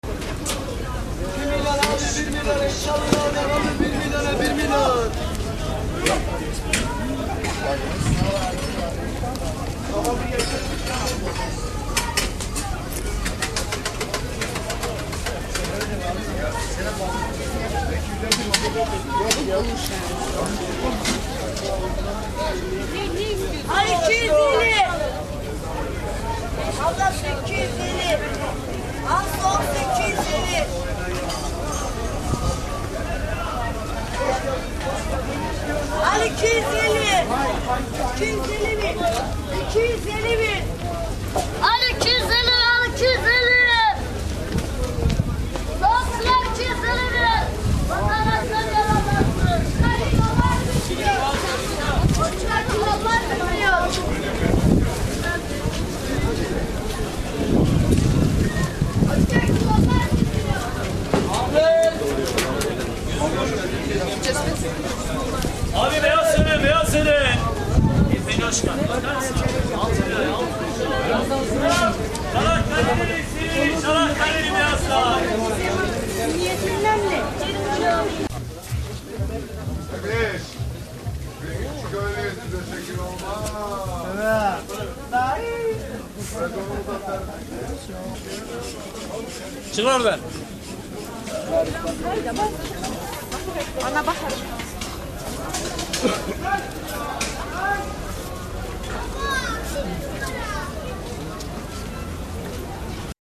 - ну, тут ничего особенного. Стамбульский базар, мальчишки кричат: bir million-bir million-bir milioooon! - один миллион значит за продаваемую ерунду; турецкие разговоры, крики, постукивание ножей продавца донер-кебабов (шаурмы), который громко и картинно звенит, чтоб привлечь покупателей... всякое...
bazar.mp3